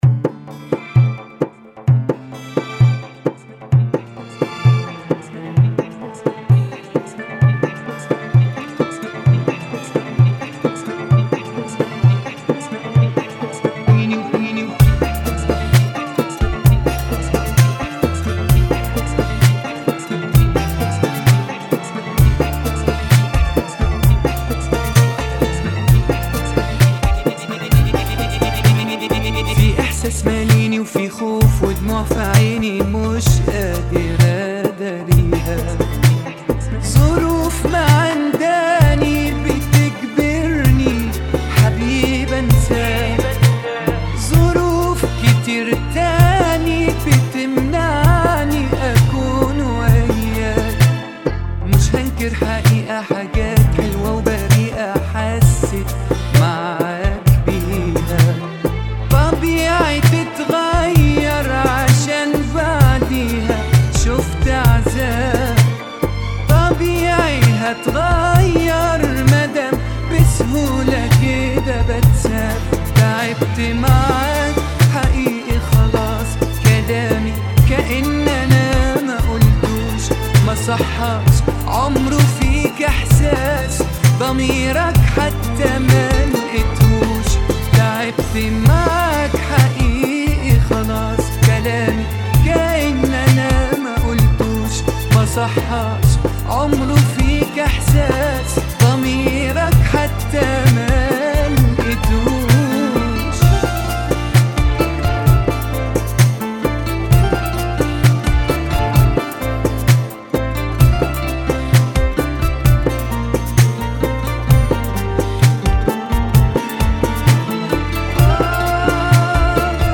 Funky [ 65 Bpm ]